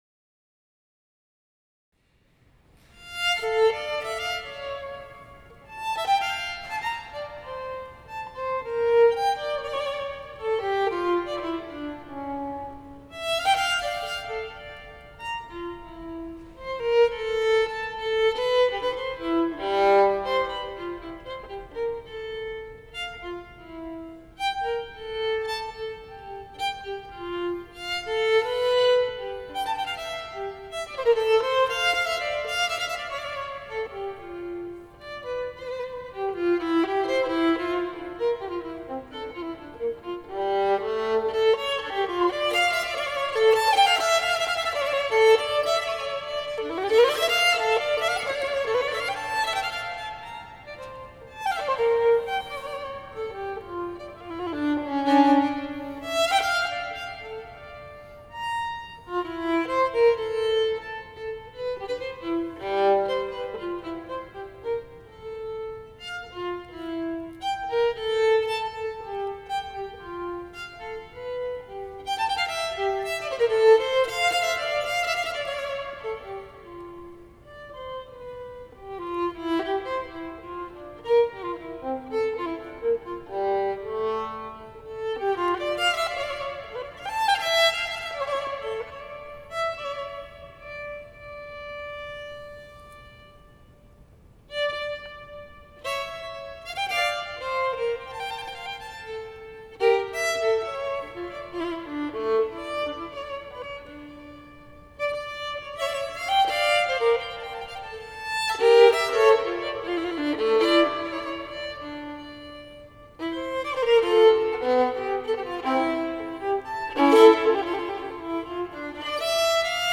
Live at
St Stephen Walbrook